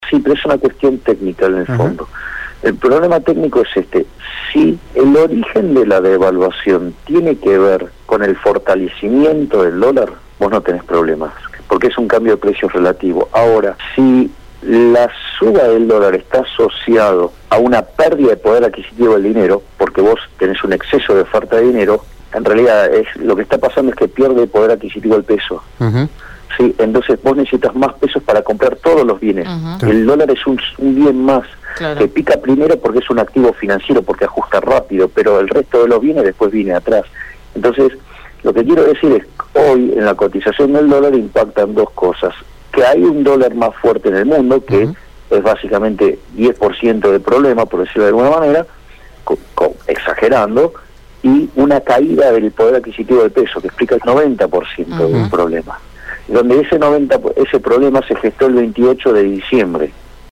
El economista mediático analizó en Radio EME la situación económica del país y la reciente convulsión por la corrida del dólar.